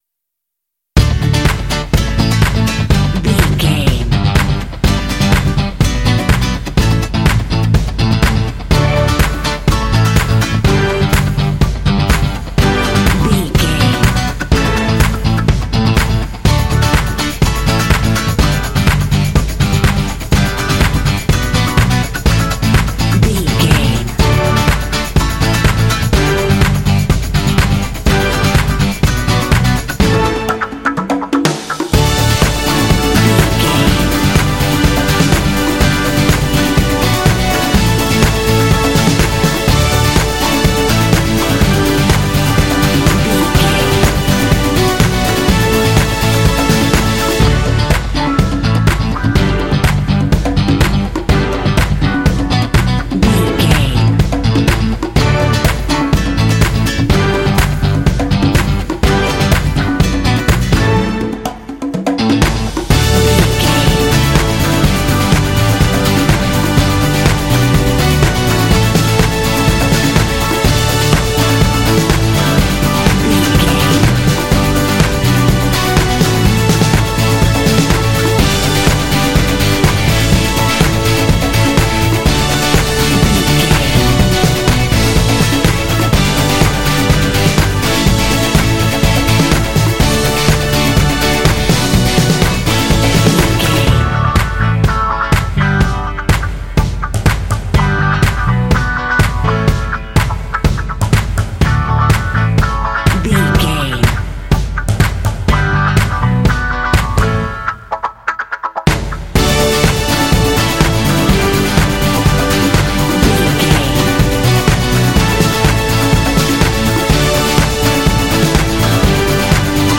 Uplifting
Ionian/Major
SEAMLESS LOOPING?
WHAT’S THE TEMPO OF THE CLIP?
cheerful/happy
funky
drums
strings
acoustic guitar
electric guitar
bass guitar
percussion
synthesiser
alternative rock